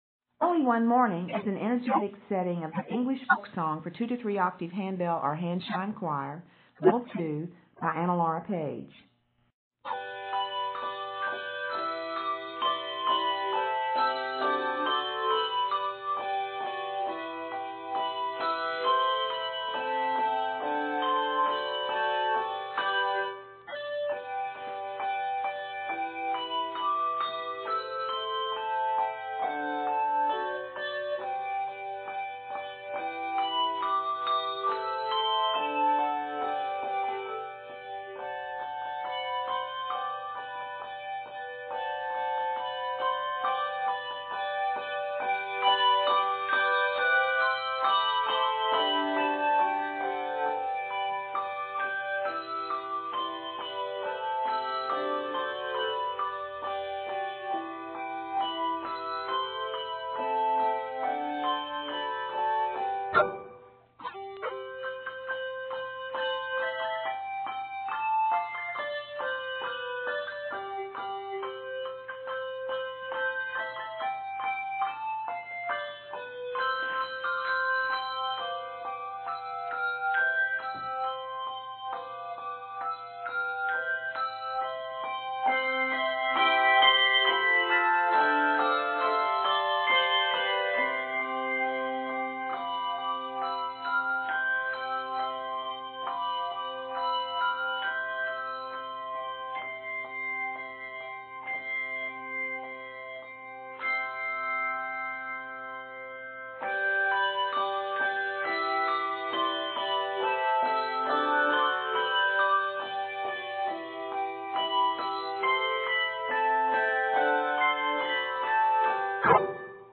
The range of dynamics goes from pp to ff.